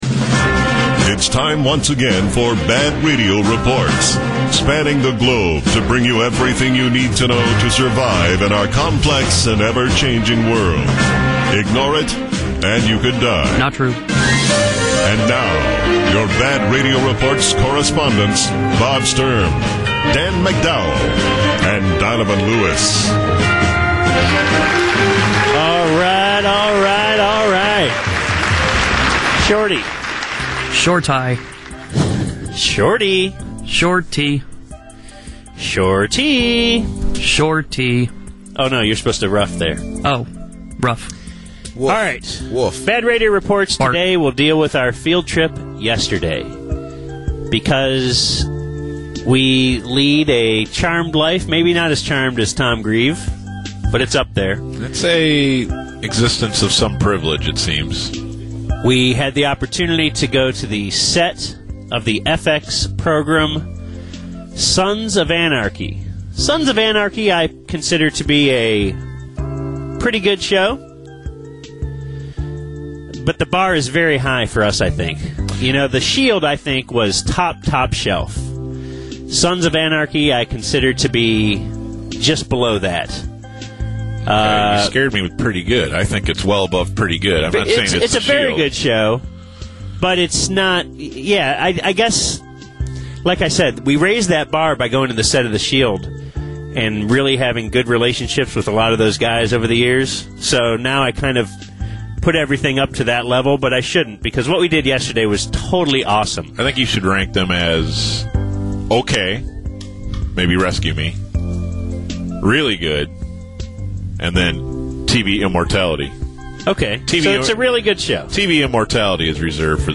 Be warned, there’s very little “bit” or “spotes” involved here – just show talk.
Segment 1 – Setup/Intro (Aug 19th) Segment 2 – Interview with Kurt Sutter, creator/producer (Aug 19th) Segment 3 – Interview with Ron Perlman aka Clay Morrow (Aug 20th)